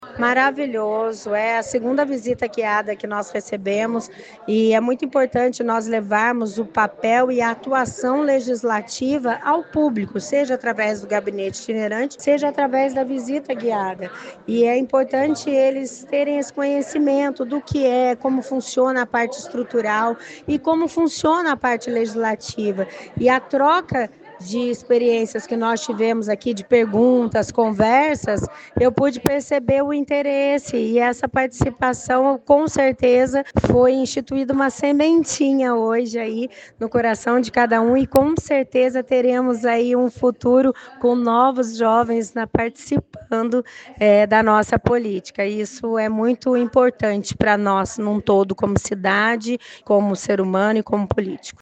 Áudio da Dra. Élida Vieira, vereadora e presidente da Câmara